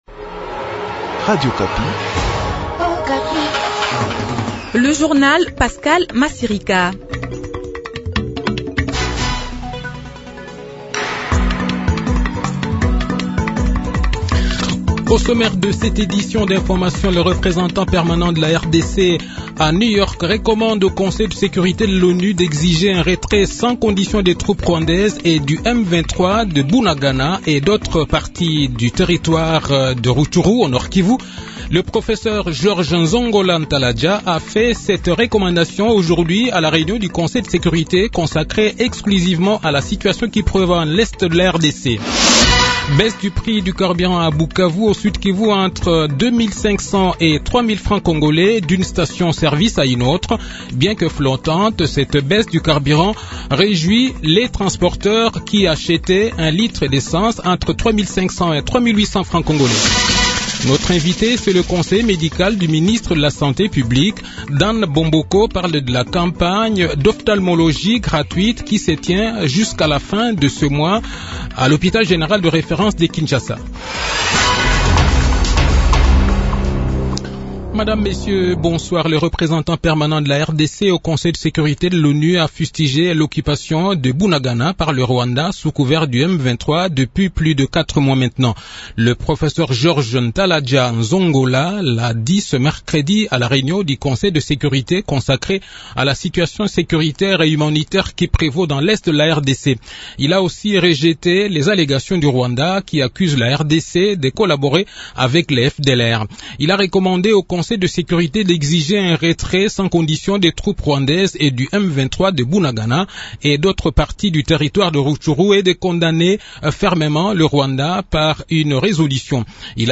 Le journal de 18 h, 26 octobre 2022